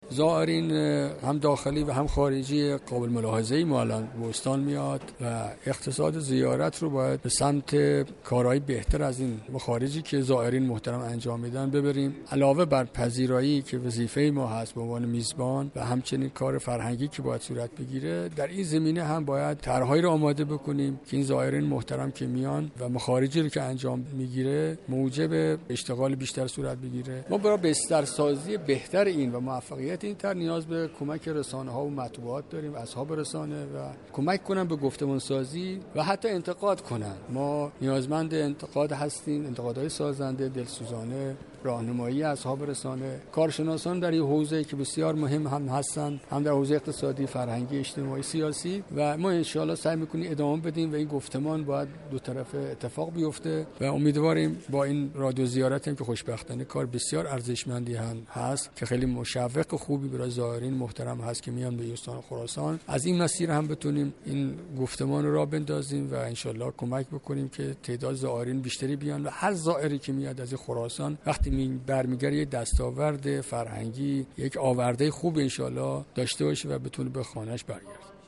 نخستین نشست صمیمی استاندار جدید خراسان رضوی با اصحاب رسانه در مشهد برگزار شد
علیرضا رزم حسینی در این جلسه با اشاره به این كه بیشترین تمركز استانداری خراسان رضوی بر رشد اقتصادی استان است گفت : توسعه اقتصاد زیارت یكی از برنامه های استانداری برای پیش برد این اهداف است.